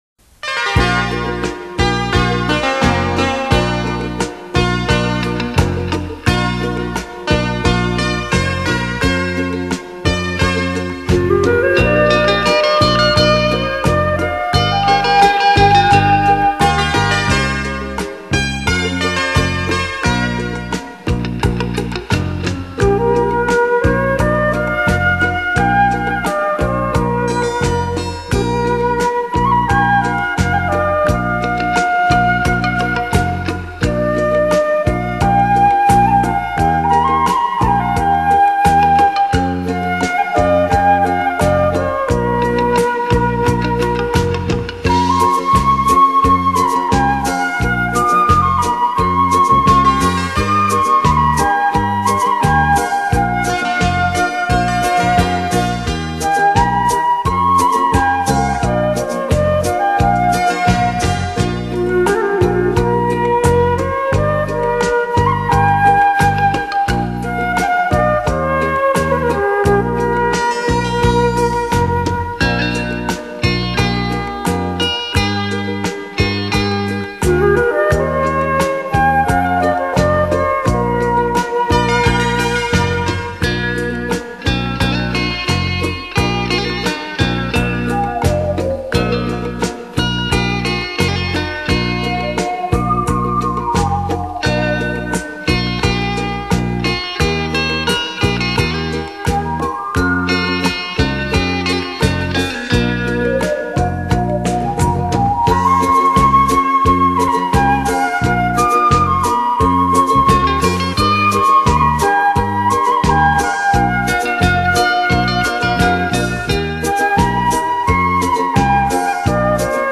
有着夜色般的朦胧，听着它---让你进入心境如梦的世界。
像小河流水，它有着清晨露珠般的清澈。